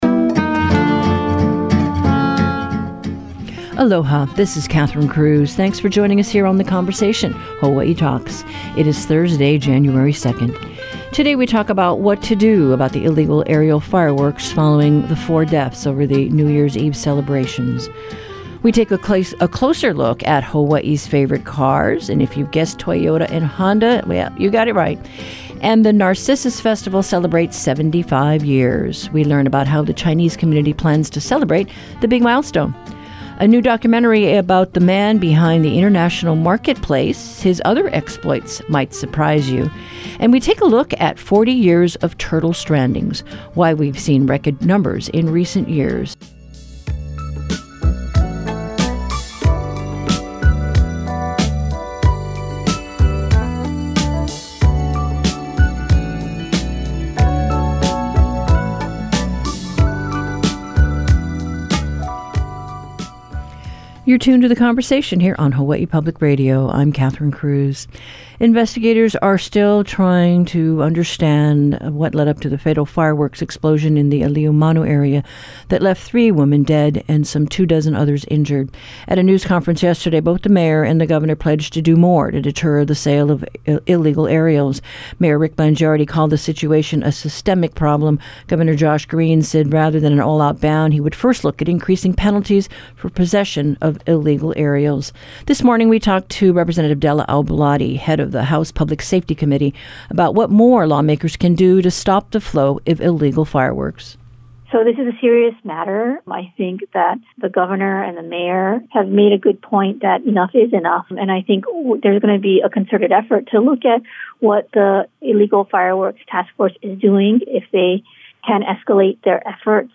Whether you live in our state or far from our shores, you’ll know what’s happening in Hawaiʻi with HPR's daily hour of locally focused discussions of public affairs, ideas, culture and the arts. Guests from across the islands and around the world provide perspectives on life in Hawaiʻi — and issues that have not yet reached Hawaiʻi.